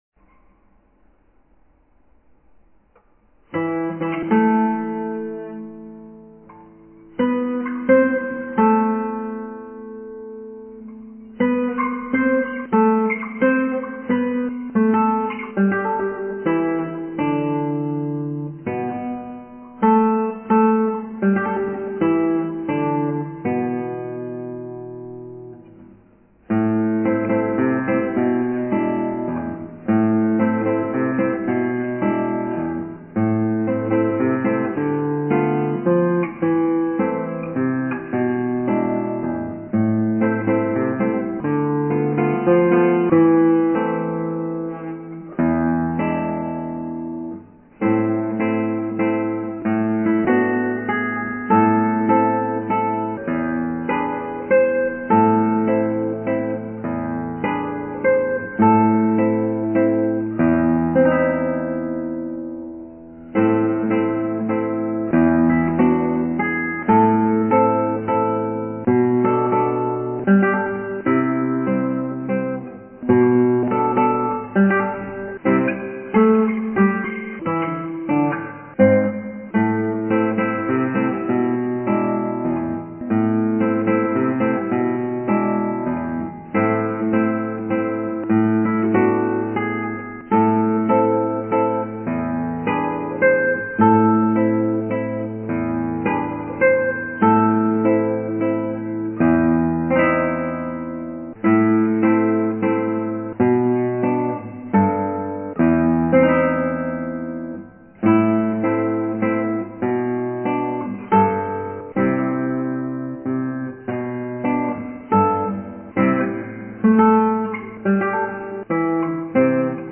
アルカンヘルで